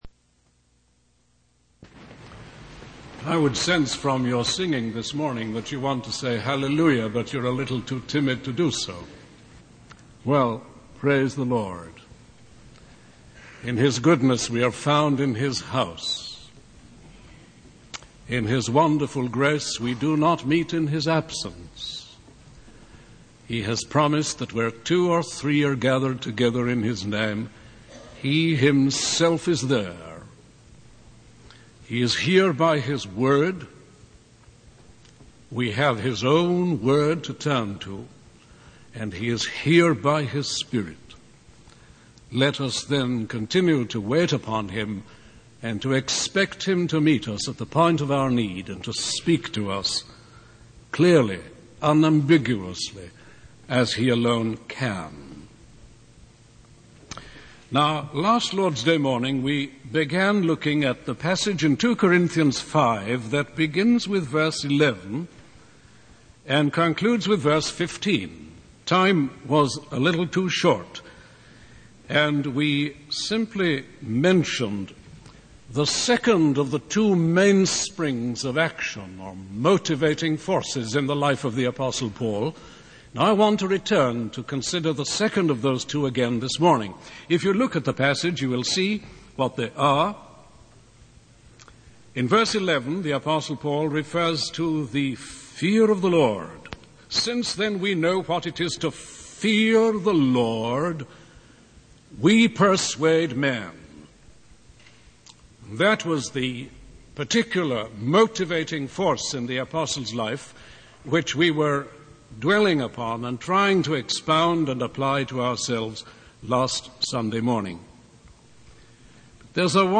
In this sermon, the speaker discusses the lack of understanding and commitment among Christians towards the love of Christ. He emphasizes that many believers talk and sing about the cross but fail to truly comprehend its significance. The speaker highlights the example of the apostle Paul, who was compelled by the love of Christ to give himself fully to the service of the Lord.